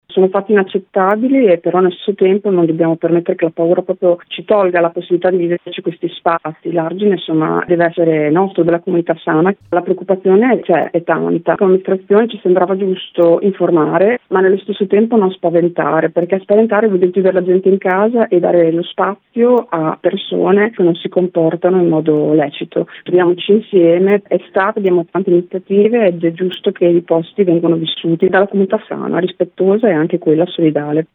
camposanto-sindaca.mp3